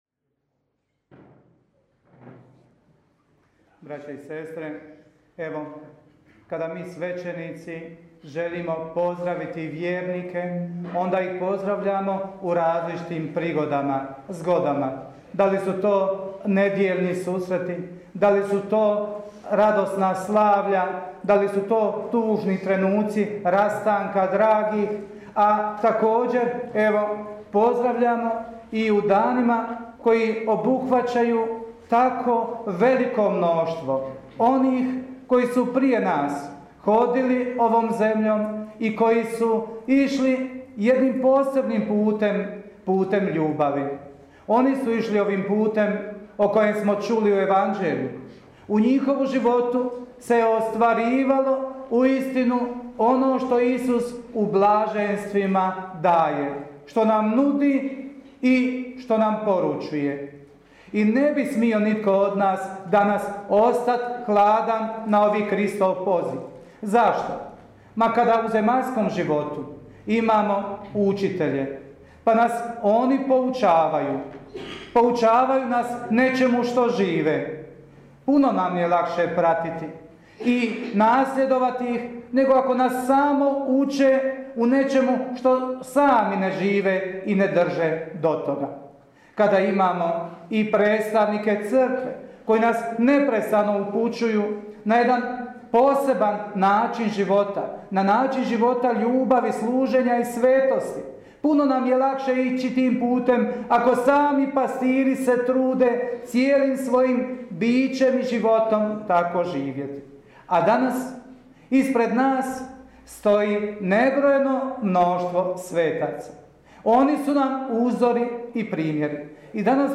PROPOVJED:
sv. MISA @ kapelica NOVO GROBLJE
SSkNG2015_a_PROPOVJED.mp3